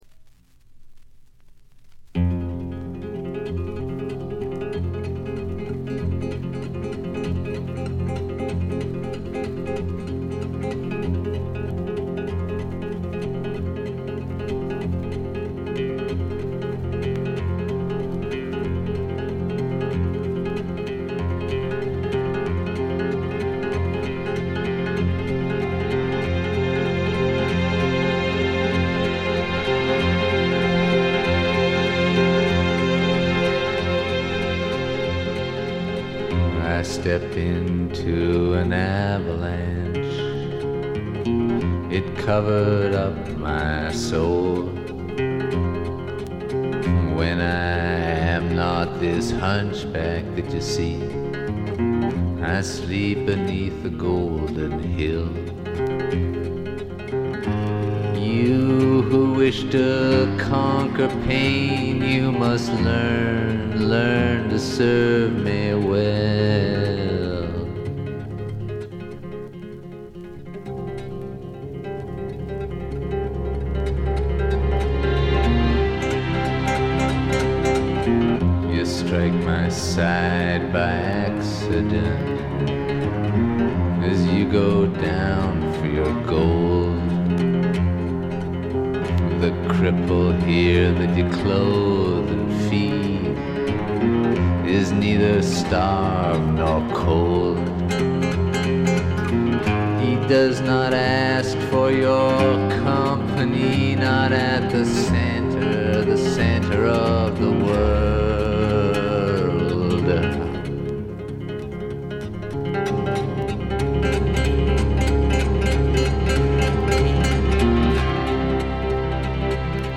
軽微なチリプチ少々。
試聴曲は現品からの取り込み音源です。